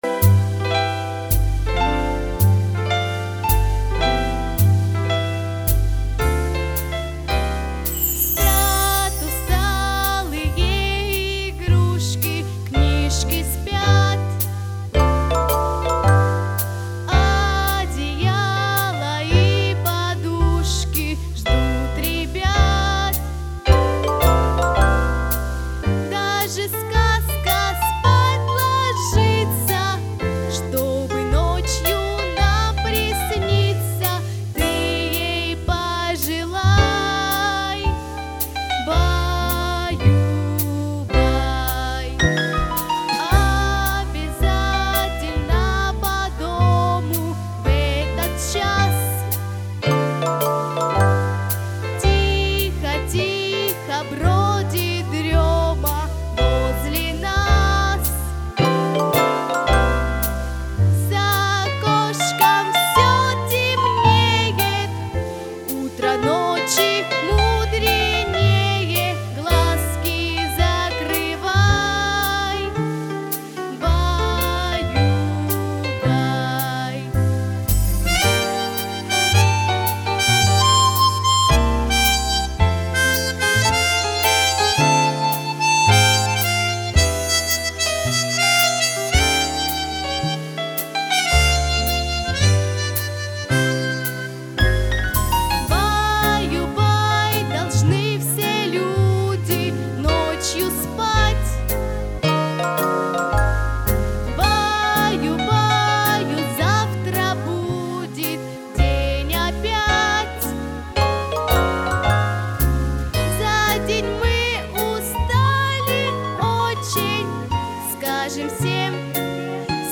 • Категория: Детские песни
🎶 Детские песни / Колыбельные песни